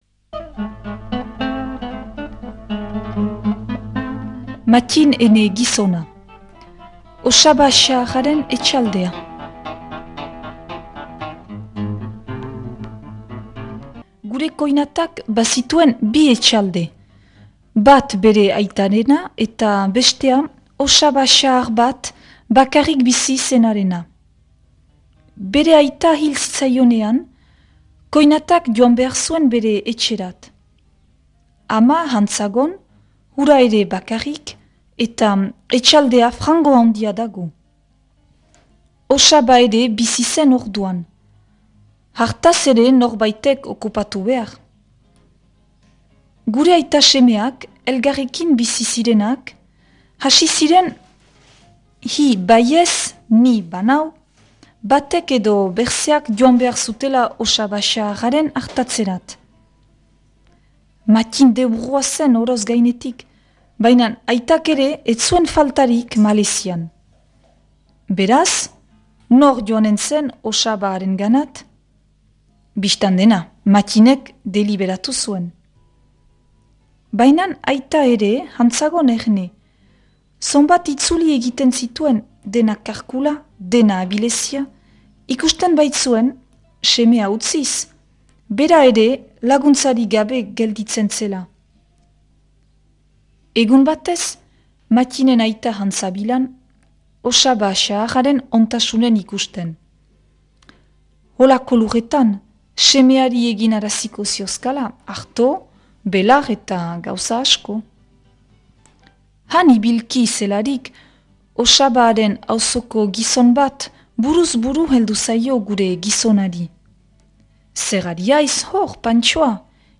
Gure artxiboetarik atera sail hau, Ahetzeko Mattin Trecu (1916-1981) bertsolariari eskainia.
proposatu irakurketa da.